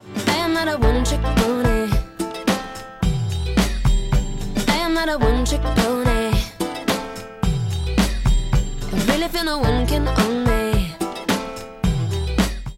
If you listen to it you’ll hear a great bass sound that’s thrown into the mix here and there in the track.
When listening to the track, we can easily recognise that the sound is based on a sine waveform.